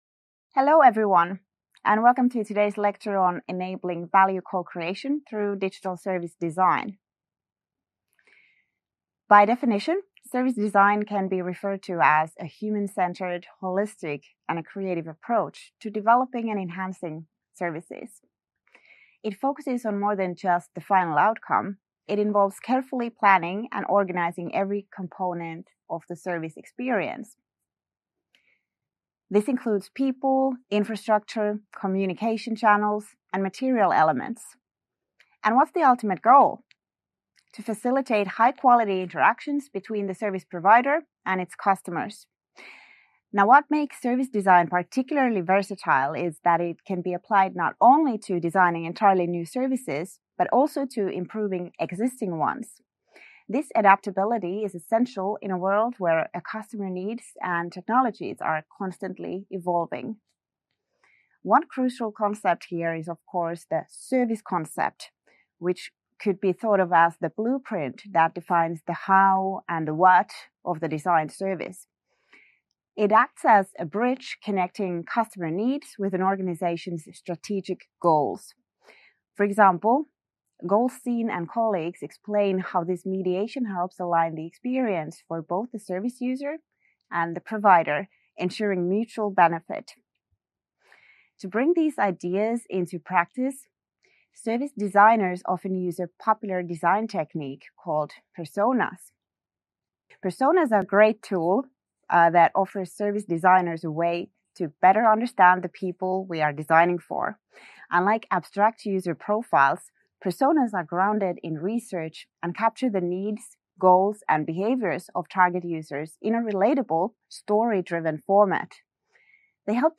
Week 3 Self-Study Video Lecture - Enabling Value Co-creation through Digital Service Design